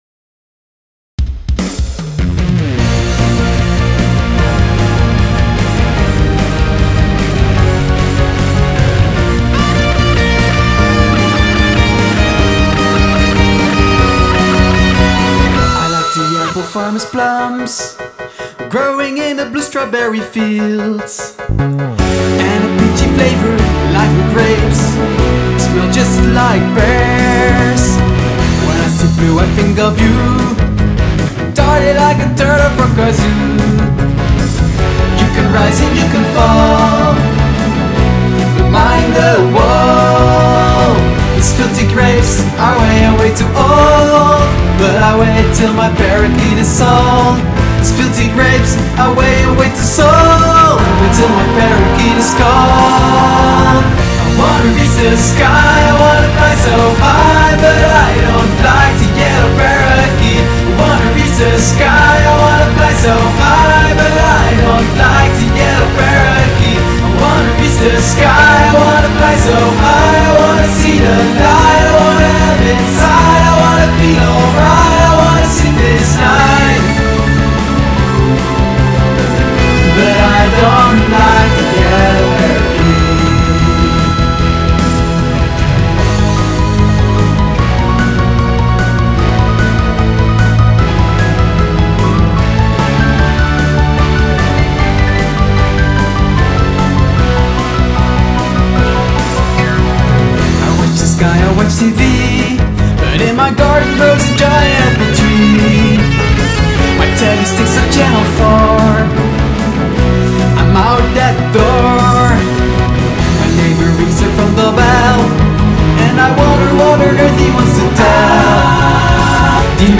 toetsen en gitaren
zang en koortjes